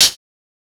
Closed Hat (IFHY).wav